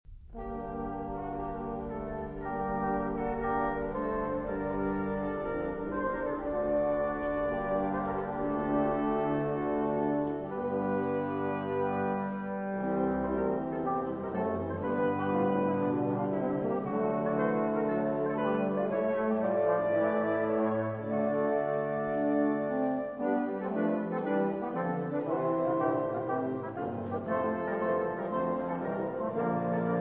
• mottetti
• musica sacra
• Motet